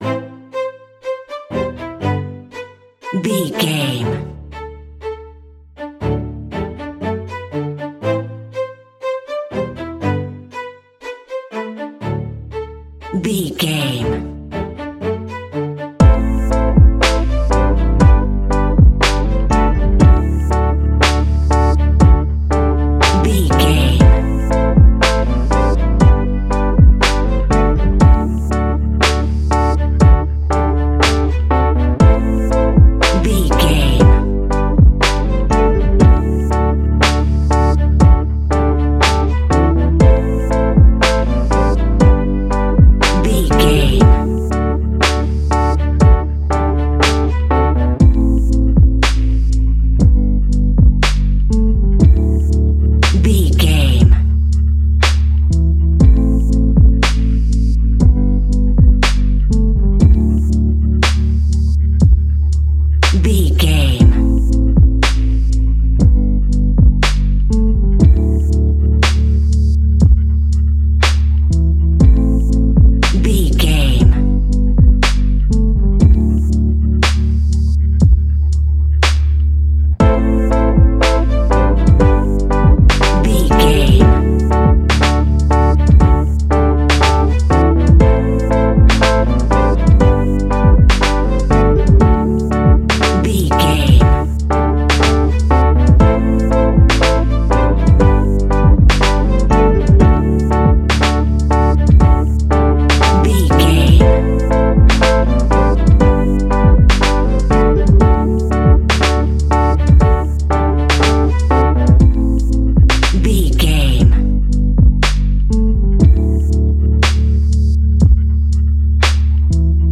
Ionian/Major
D♭
laid back
sparse
new age
chilled electronica
ambient
atmospheric
morphing